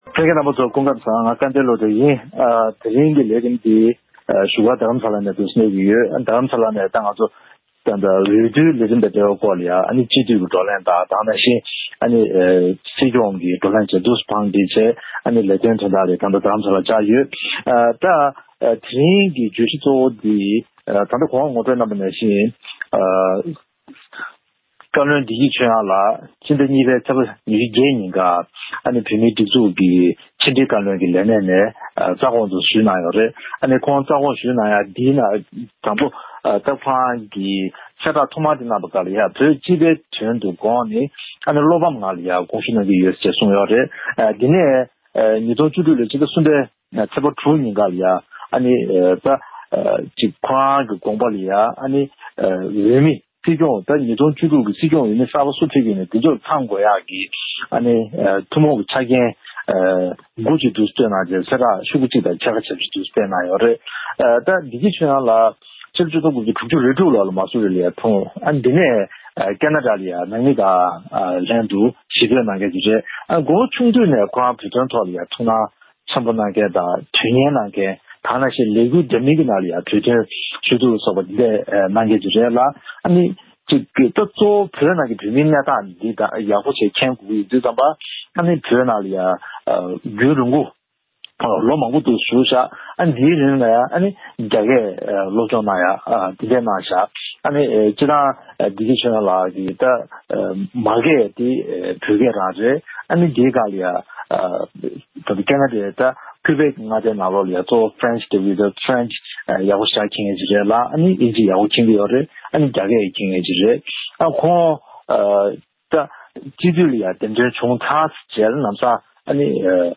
༄༅། །ཐེངས་འདིའི་གནད་དོན་གླེང་མོལ་གྱི་ལེ་ཚན་ནང་།